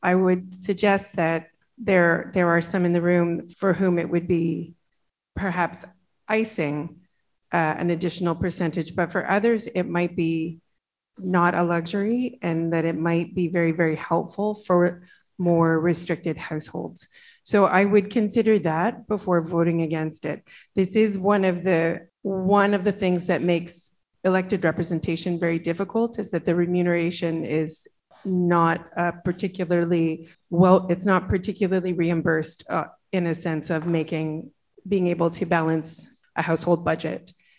Councillor MacNaughton explained that for those who are not well off the increase could be a difference maker.